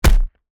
body_hit_small_79.wav